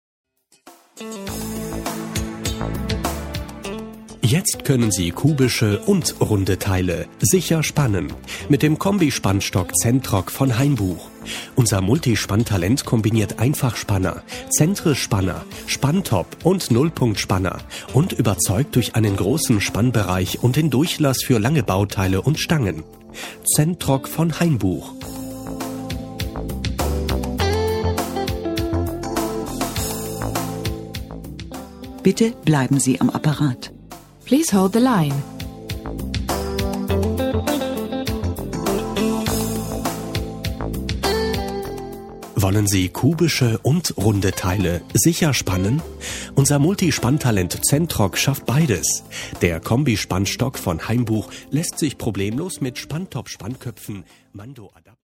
Kundenportrait_Werbespot_Hainbuch_1.mp3